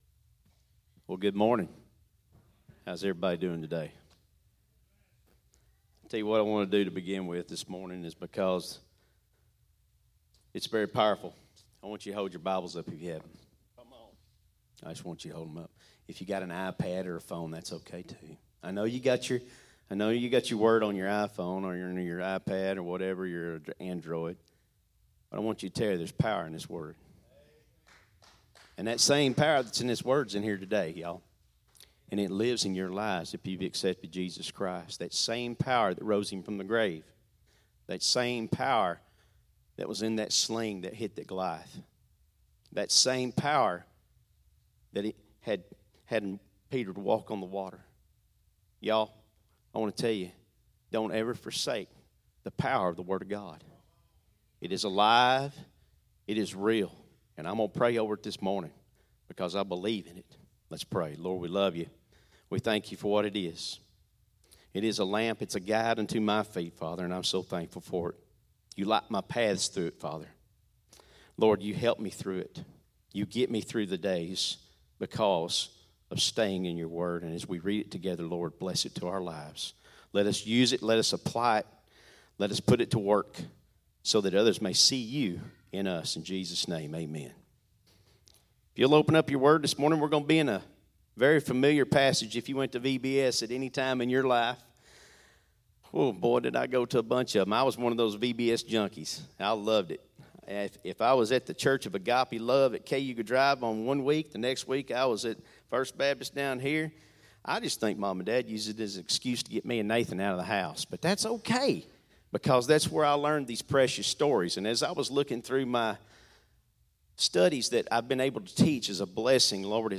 Sermons | Living for the Brand Cowboy Church of Athens